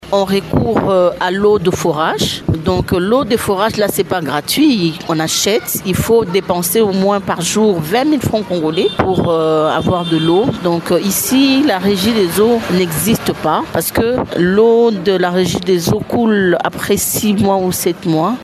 Au quartier Ngomba‑Kikusa, dans la commune de Ngaliema, la population affirme être à bout.
Une femme confie recourir aux forages aménagés pour couvrir les besoins de sa famille en eau potable.